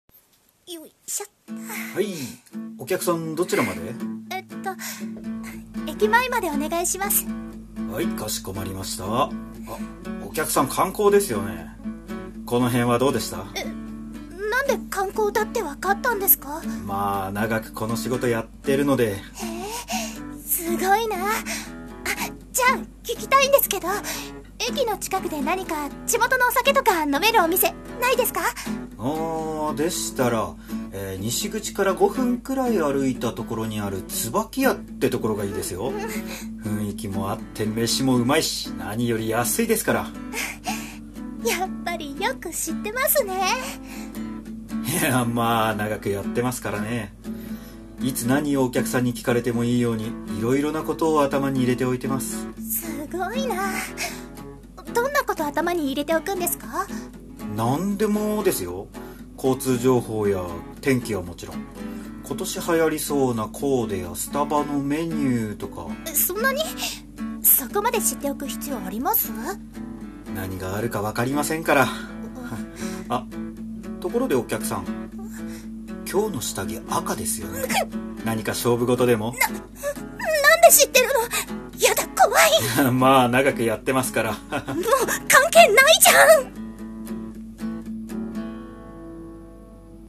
声劇コメディ「タクシーの運転手」